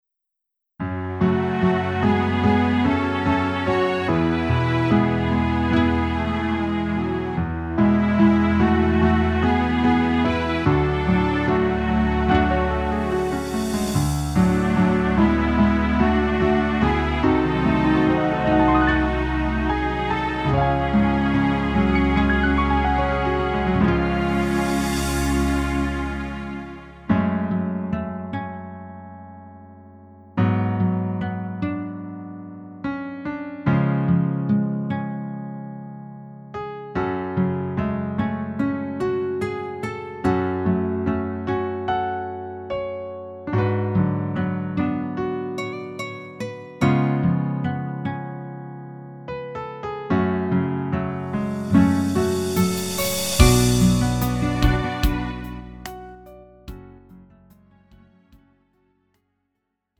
음정 원키 4:19
장르 가요 구분 Lite MR
Lite MR은 저렴한 가격에 간단한 연습이나 취미용으로 활용할 수 있는 가벼운 반주입니다.